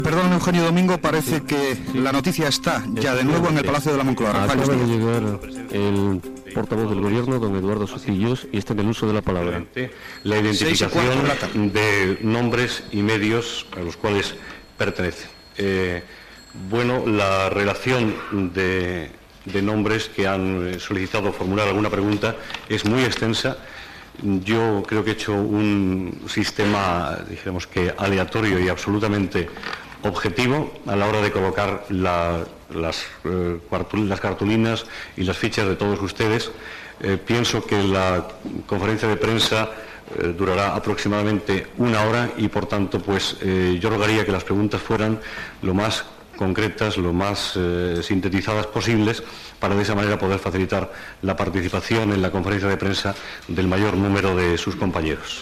Transmissió des del Palacio de la Moncloa de la roda de premsa després del primer Consell de Ministres presidit per Felipe González.
Paraules del portaveu del govern Eduardo Sotillos sobre els mitjans de comunicació que volen fer preguntes al president del govern.
Informatiu